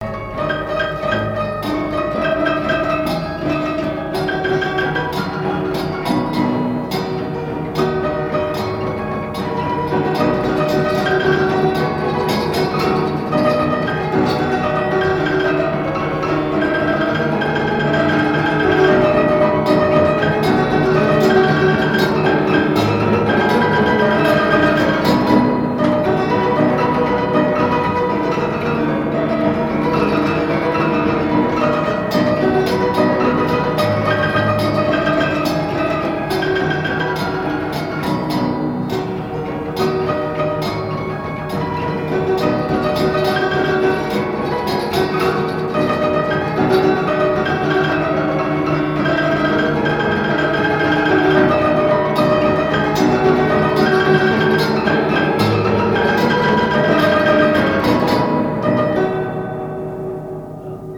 danse : marche
Genre strophique
Pièce musicale inédite